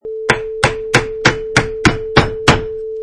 Sound bytes: Hammer on Nail 8 Hits
Eight high quality hits of a hammer on a nail, no background noise
Product Info: 48k 24bit Stereo
Category: Tools / Hand Tools - Hammers
Try preview above (pink tone added for copyright).
Hammer_on_Nail_8_Hits.mp3